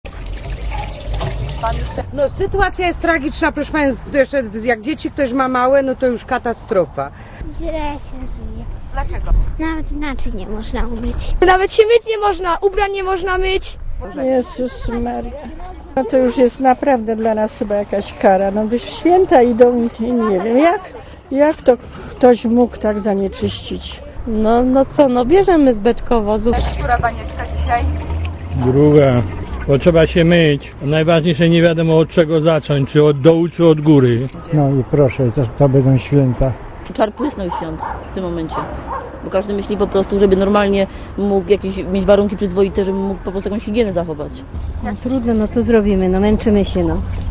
Mówią mieszkańcy Suchej Beskidzkiej